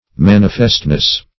Search Result for " manifestness" : The Collaborative International Dictionary of English v.0.48: Manifestness \Man"i*fest*ness\, n. The quality or state of being manifest; obviousness.